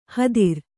♪ hadir